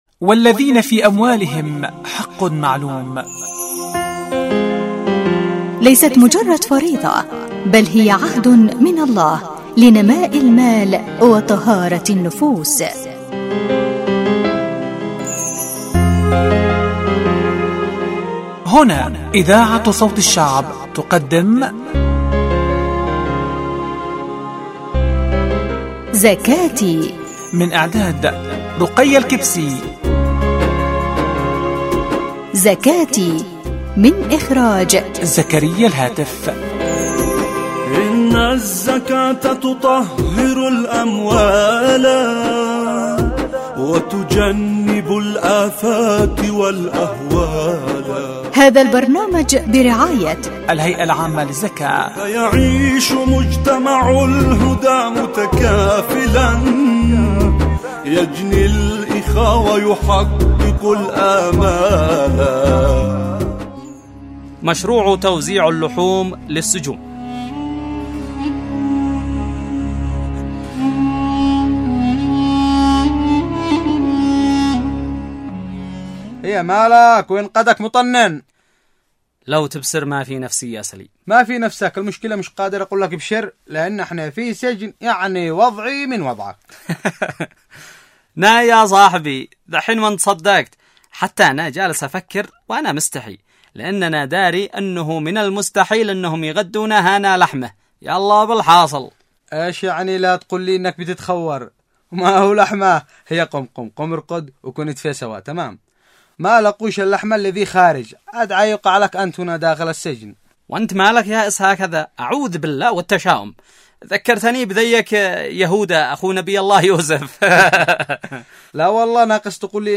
البرامج الحوارية زكاتي زكاتي ح14